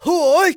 xys蓄力7.wav 0:00.00 0:00.58 xys蓄力7.wav WAV · 50 KB · 單聲道 (1ch) 下载文件 本站所有音效均采用 CC0 授权 ，可免费用于商业与个人项目，无需署名。
人声采集素材